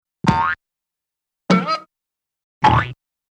B_BAGARRE.mp3